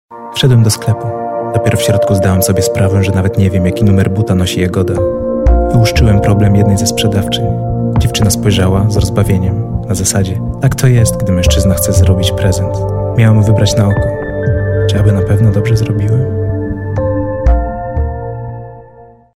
Locuteur natif
Démo 1